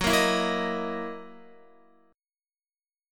Gb13 Chord
Listen to Gb13 strummed